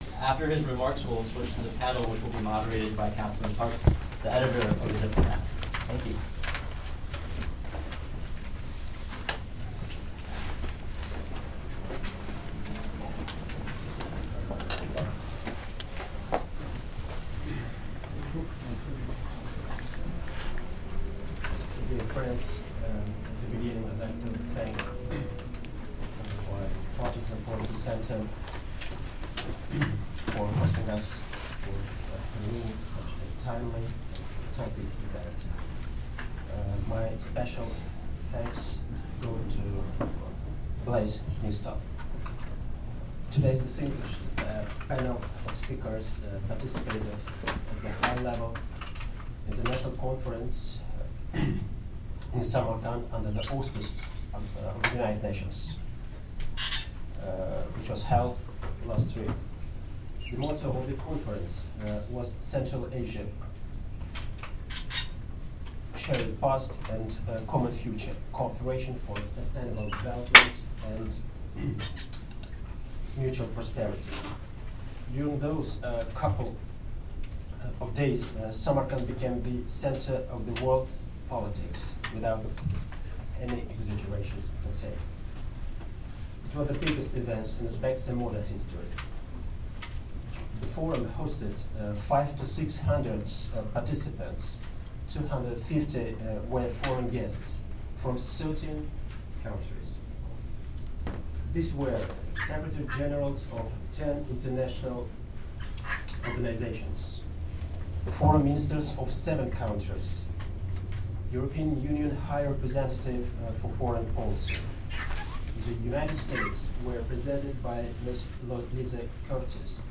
Uzbekistan discussion at Bipartisan Policy Center, Nov 21, 2017